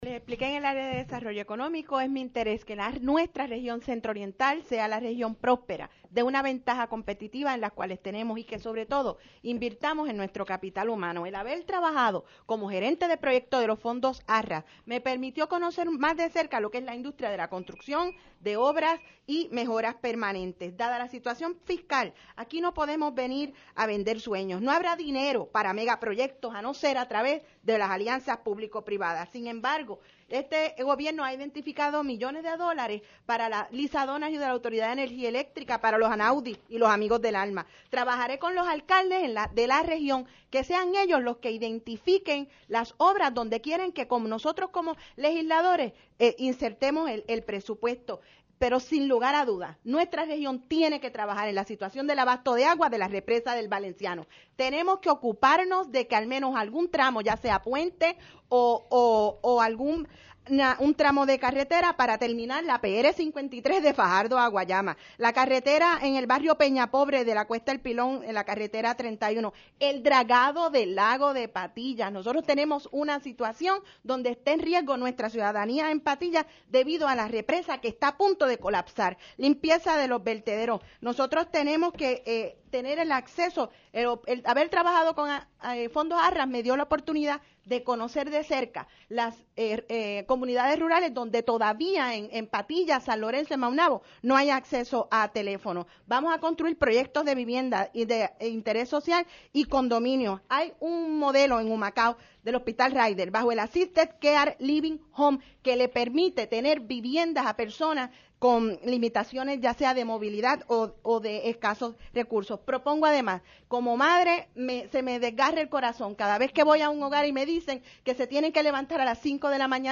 Segundo Debate PNP Senadores Humacao
(4 de mayo de 2016)-El segundo grupo de aspirantes al Senado por el Distrito de Humacao, acudió ayer hasta los estudios de WALO Radio a presentar sus ideas en el quinto Debate WALO, la Contienda Primarista.